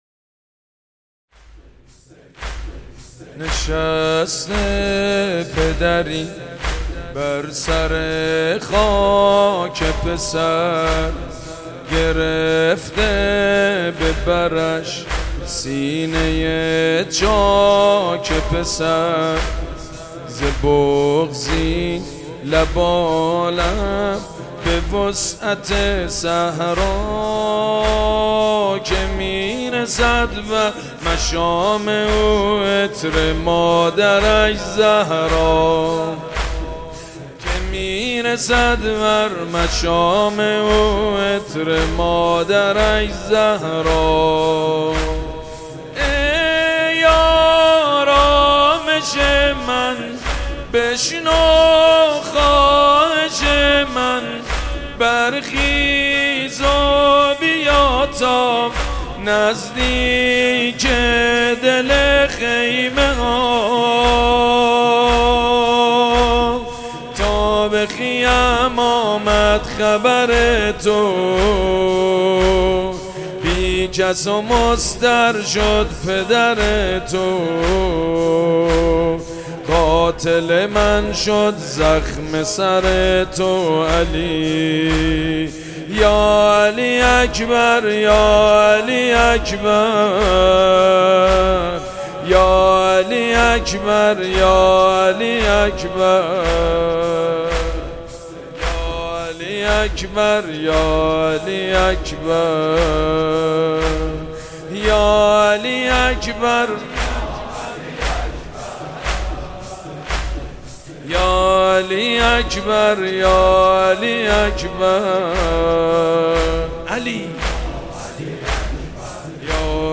گلچین مداحی شب هشتم محرم
مداحی شب هشتم محرم با صدای سید مجید بنی فاطمه